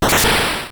P3D-Legacy / P3D / Content / Sounds / Battle / Damage / SuperEffective.wav